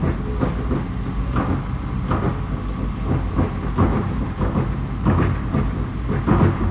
CONSTRUCTION_Digger_Loading_01_loop_stereo.wav